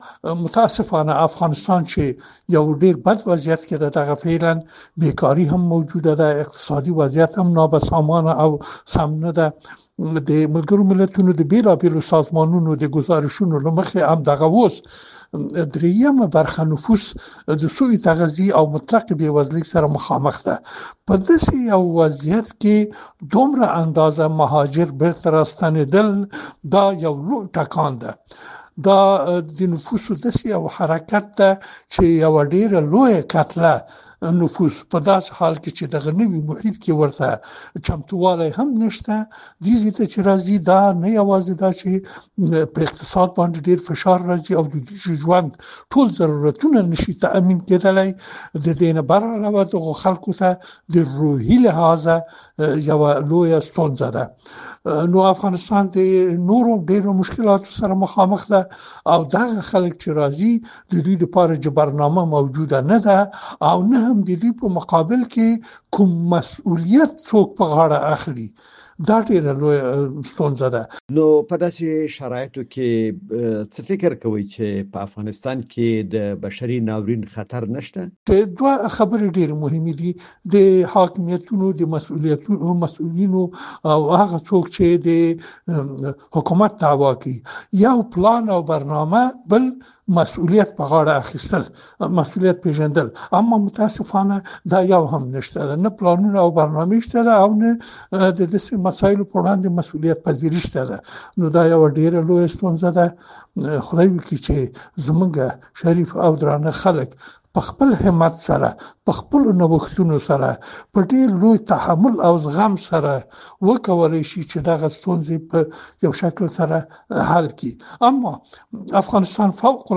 مرکه - صدا